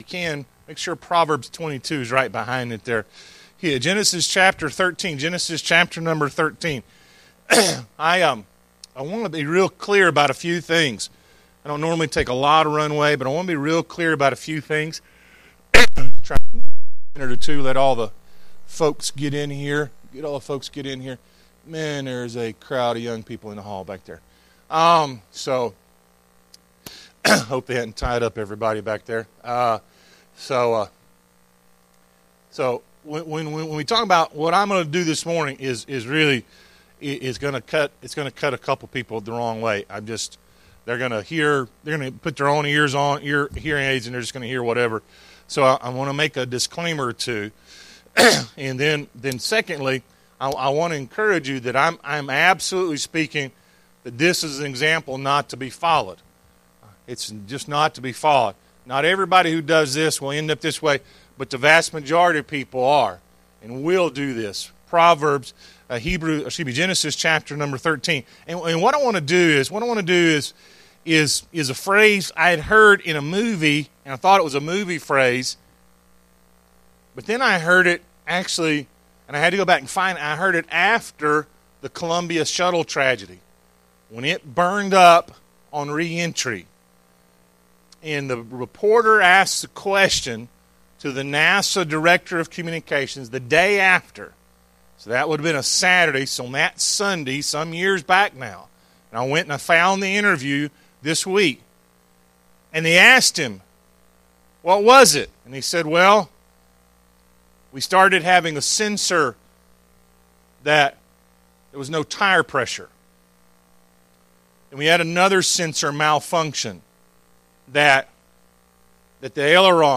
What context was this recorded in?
Genesis 13:1–13 Service Type: Sunday AM 1.